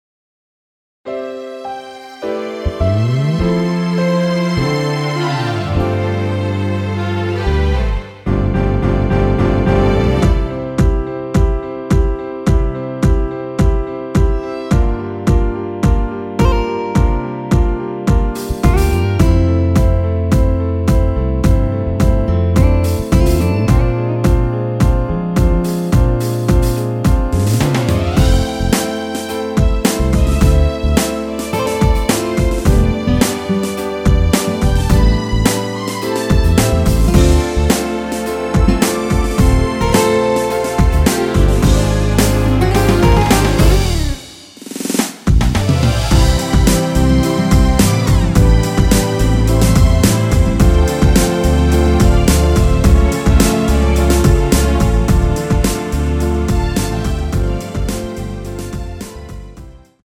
노래방에서 음정올림 내림 누른 숫자와 같습니다.
음정은 반음정씩 변하게 되며 노래방도 마찬가지로 반음정씩 변하게 됩니다.
앞부분30초, 뒷부분30초씩 편집해서 올려 드리고 있습니다.
중간에 음이 끈어지고 다시 나오는 이유는
위처럼 미리듣기를 만들어서 그렇습니다.